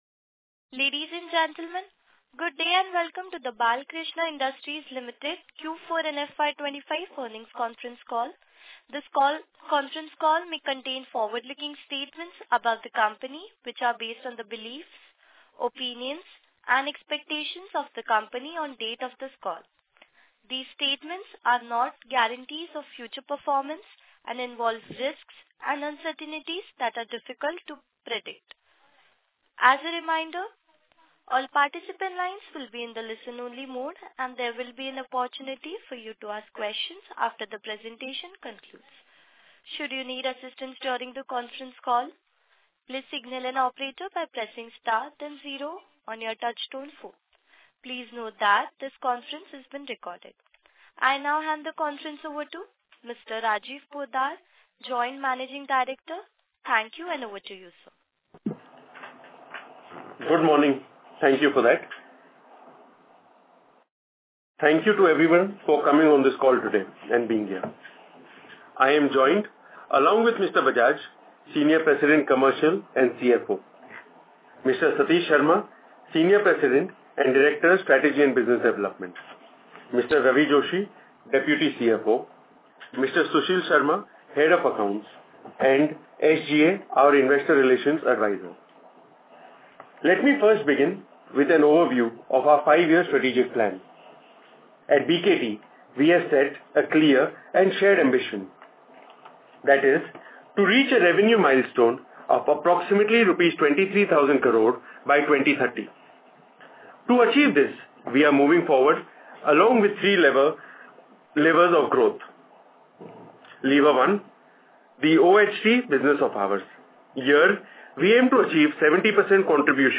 Audio recordings of conference Call dated 24th May, 2025